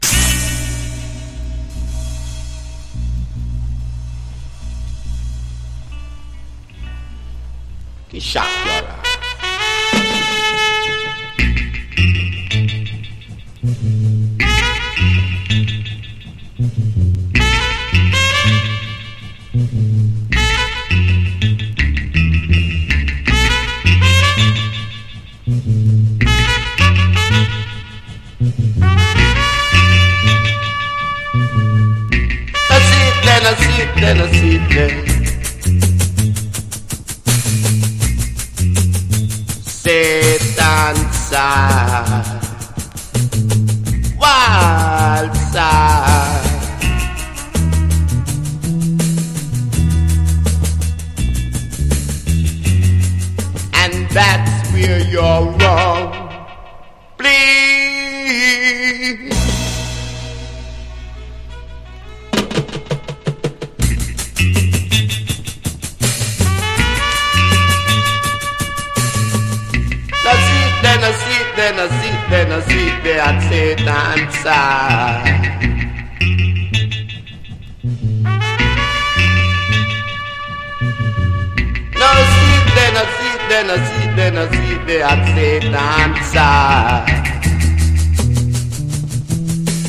• REGGAE-SKA
1. REGGAE >
# ROOTS# DUB / UK DUB / NEW ROOTS